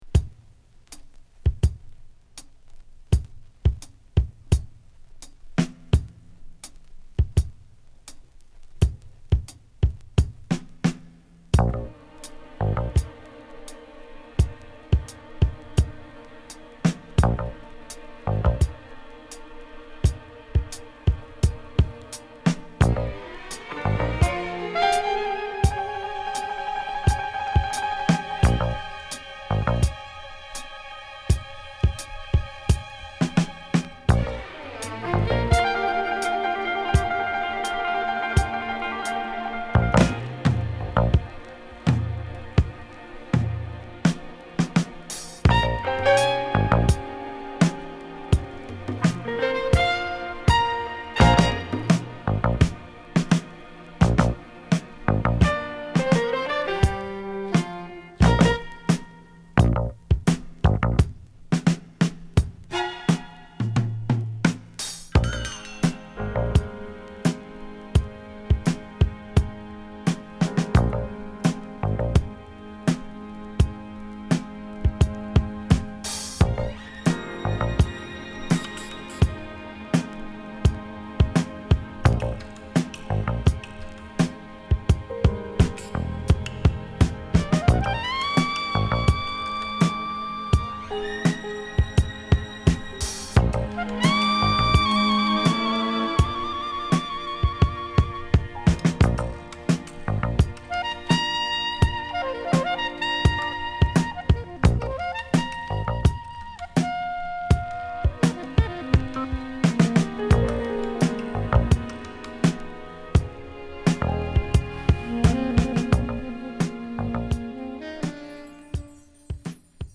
テキサス出身のSax奏者